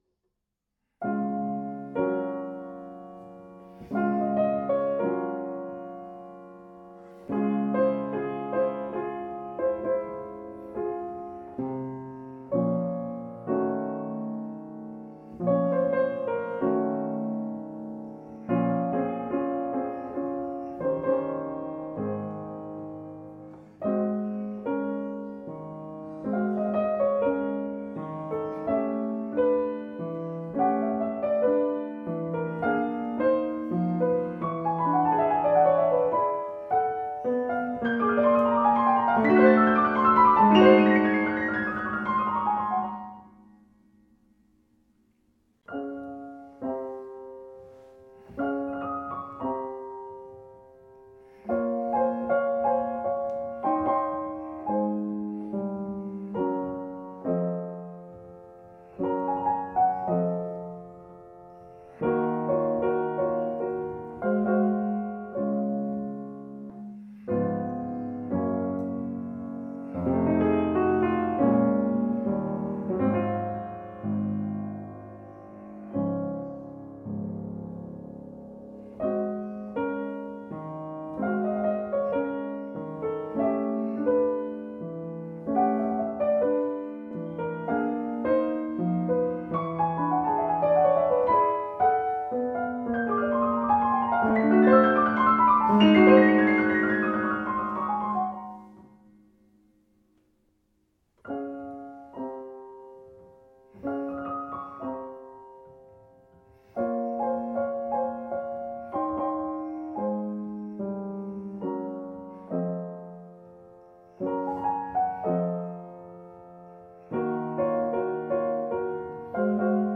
Steinway Upright Piano, 1924, Unequal Temperament